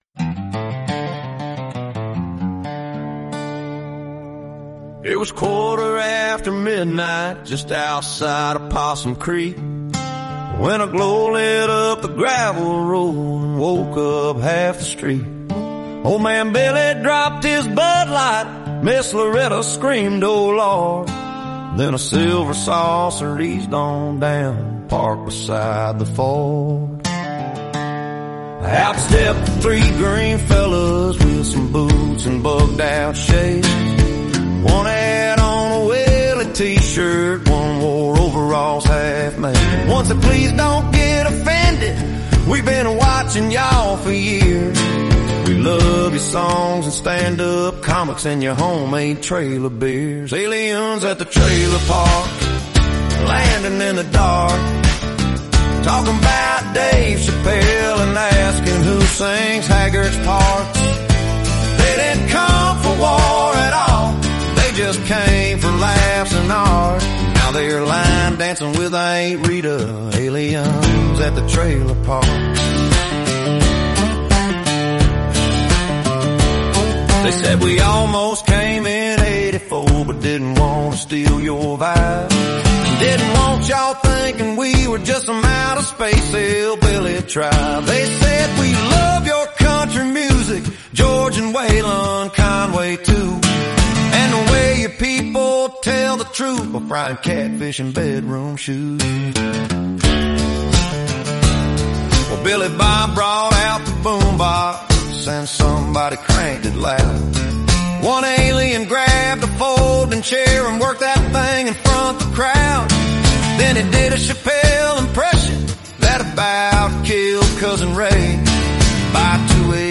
Novelty country • comedy concept • cosmic trouble
Style Country-comedy novelty with big visual hooks